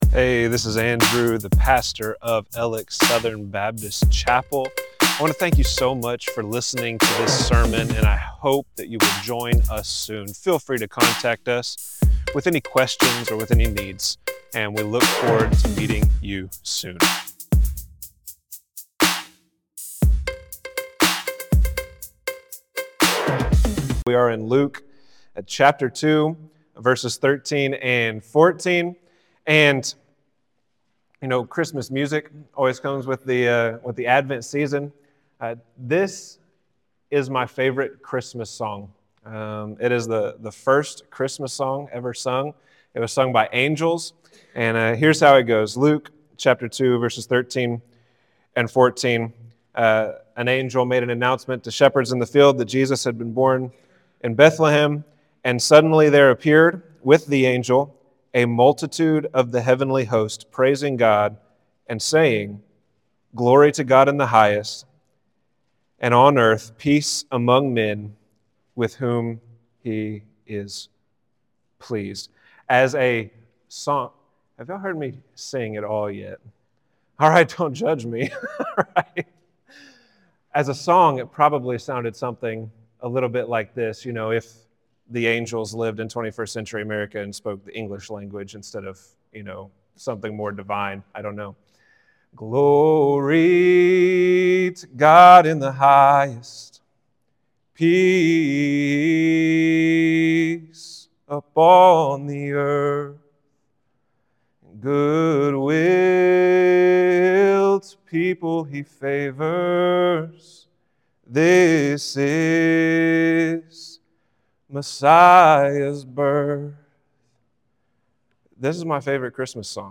Alex Southern Baptist Chapel Sermons